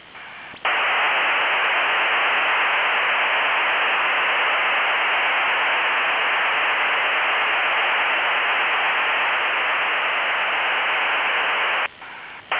Начало » Записи » Радиоcигналы классифицированные
Запись сигнала MIL-STD 188-110C App.D BW 3 kHz Waveform Number 8 QAM-16